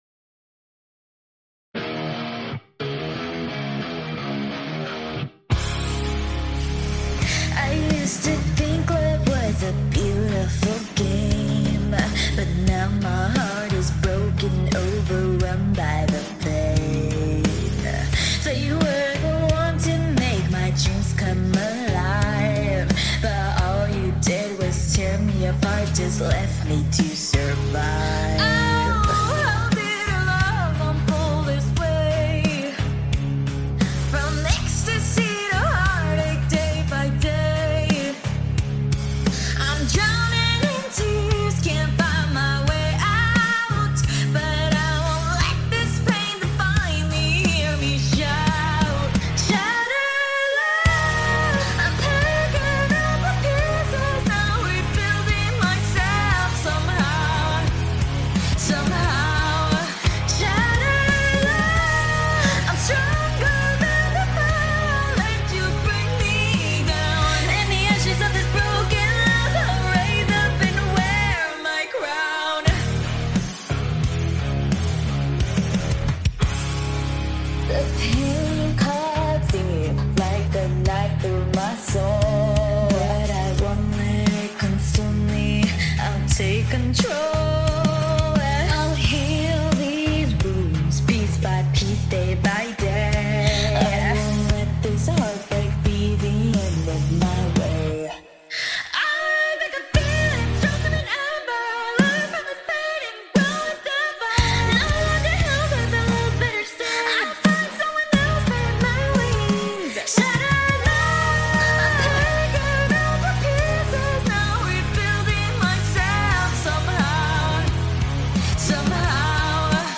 I’ve been working on it for a while and finally have the Demo Version up for people to hear!!